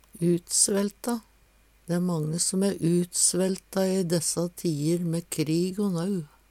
DIALEKTORD PÅ NORMERT NORSK utsvelta fått for lite mat, magre Eksempel på bruk Dæ æ mange som æ utsvelta i dessa tier mæ krig o nau.